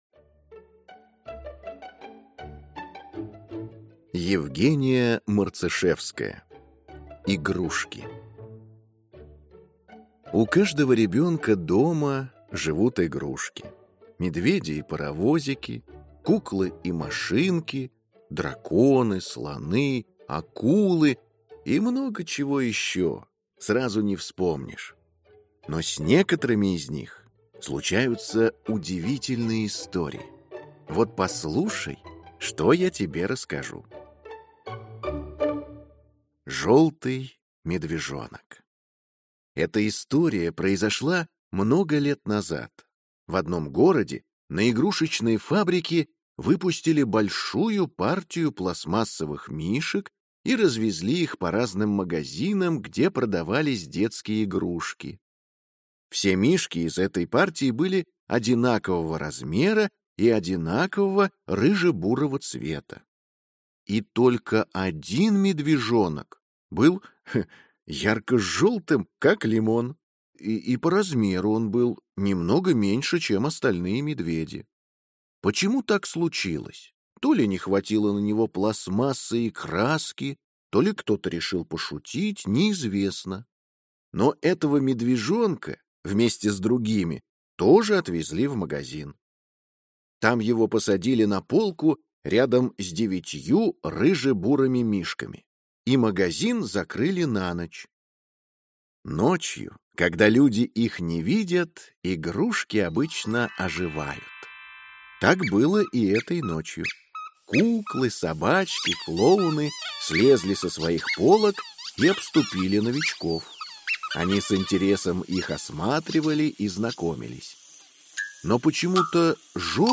Аудиокнига Игрушки | Библиотека аудиокниг